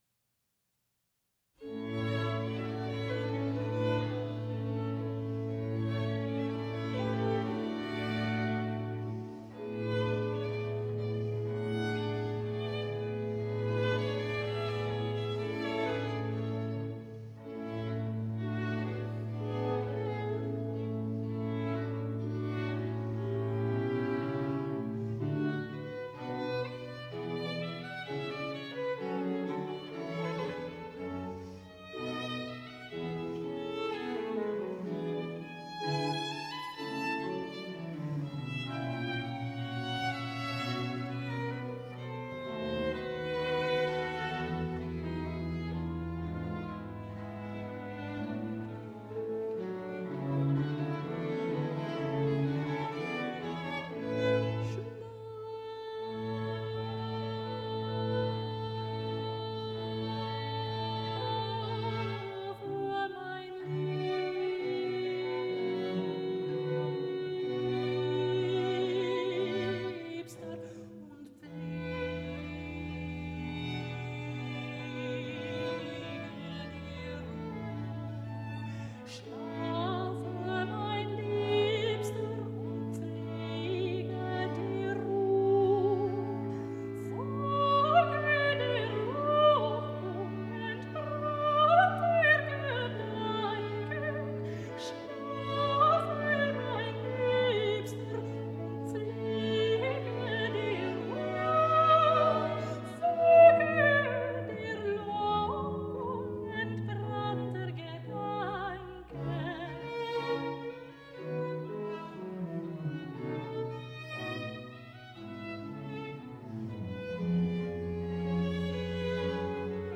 Lilting renaissance & baroque vocal interpretations .